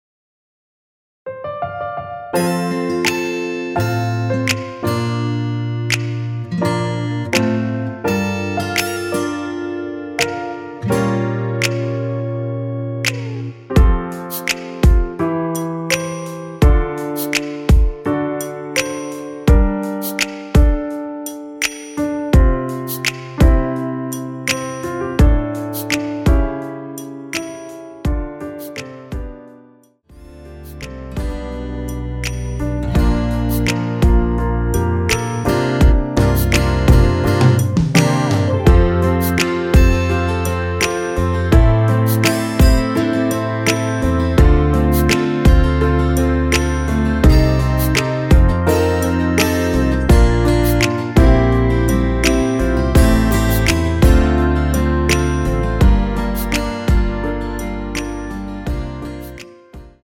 원키에서(+3)올린 MR입니다.
앞부분30초, 뒷부분30초씩 편집해서 올려 드리고 있습니다.
중간에 음이 끈어지고 다시 나오는 이유는